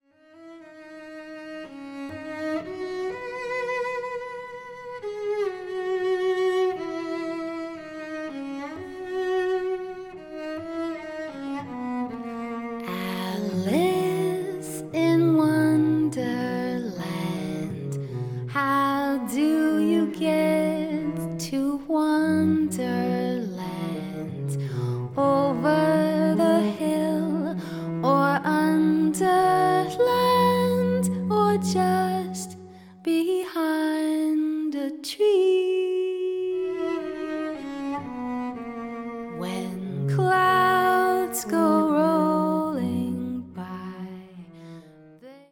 カナダ人ジャズ・シンガー